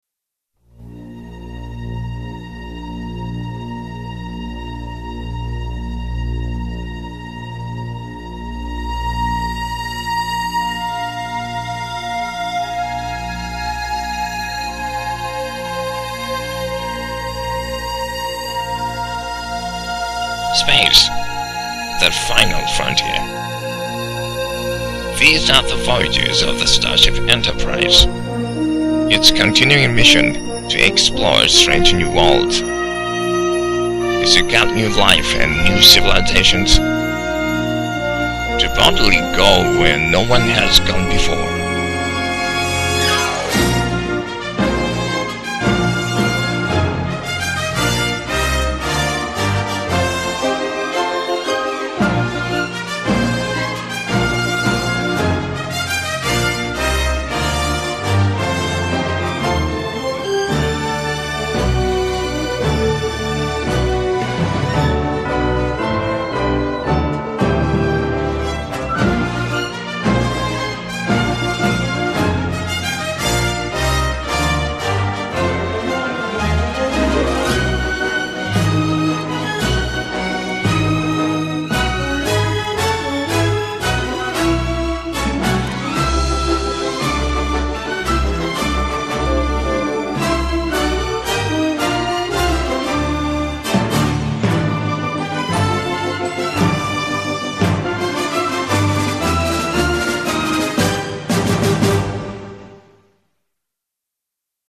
今日は酒も入っていて機嫌がいいので、ちょっとやってみました。
私がわかる箇所は英国英語風で言ってみました。
worldの発音は米国と英国ではかなり違いますね。